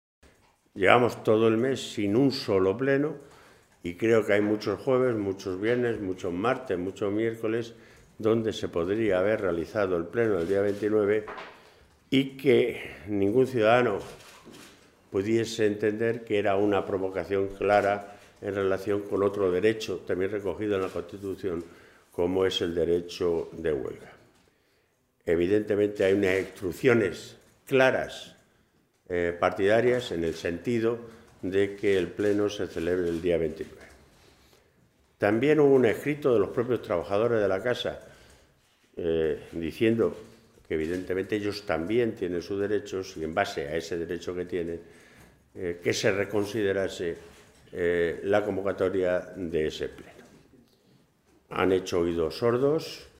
Jesús Fernández Vaquero, Secretario de Organización del PSOE de Castilla-LA Mancha
Vaquero se pronunciaba de esta manera en rueda de prensa en el Parlamento regional tras la reunión de la Mesa y la Junta de portavoces, en la que se ha fijado para el próximo jueves, día 29, coincidiendo con la huelga general convocada por los sindicatos, el próximo Pleno de la Cámara.
Cortes de audio de la rueda de prensa